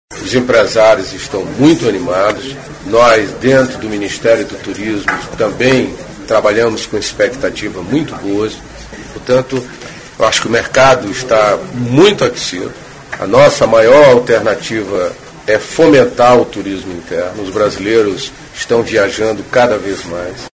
Ministro do Turismo participa de encontro em São Paulo e revela como o país se prepara para os grandes eventos
aqui para ouvir declaração do ministro Gastão Vieira sobre a perspectiva otimista quanto ao turismo no país.